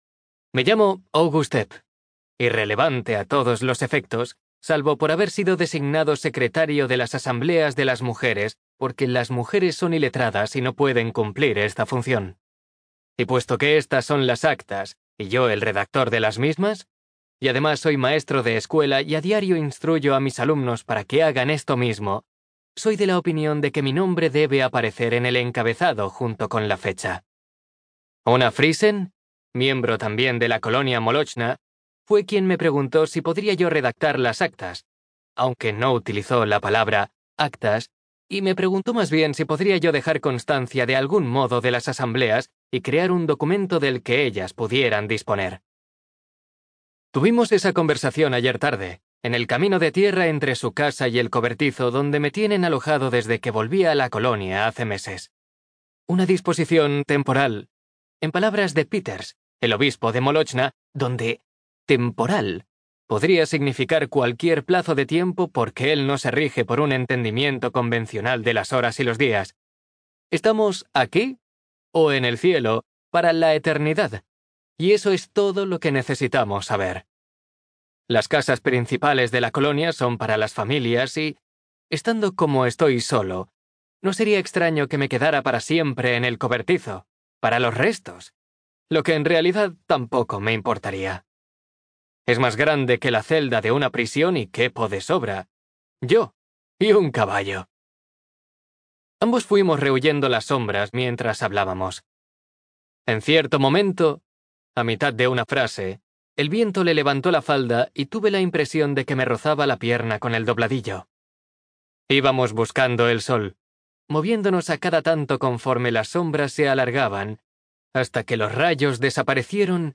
TIPO: Audiolibro CLIENTE: Audible Inc. ESTUDIO: Eclair Barcelona